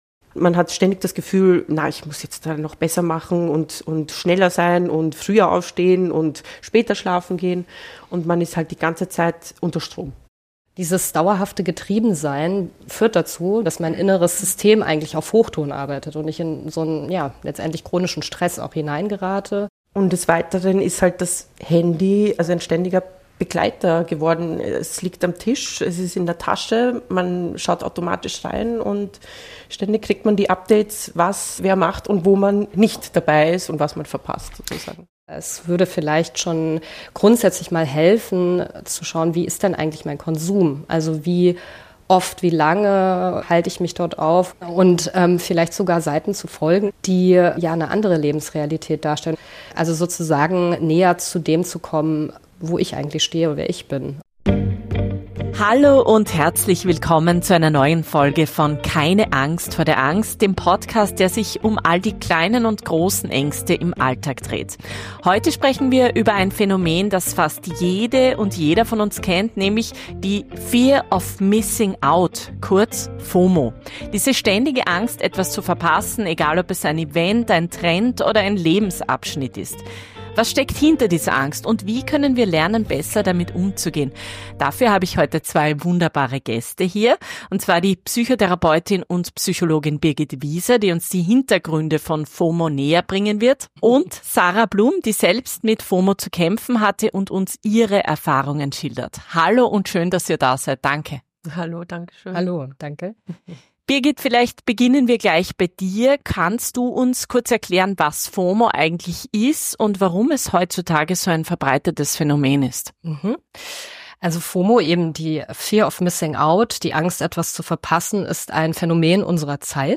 Psychotherapeutin, sie erläutert die Hintergründe und psychologischen Auswirkungen von FOMO.